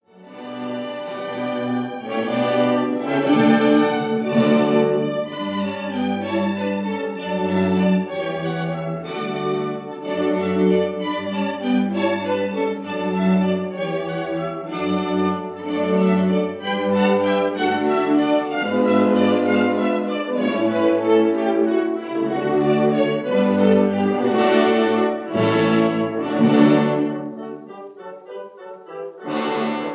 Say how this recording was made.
Recorded in 1936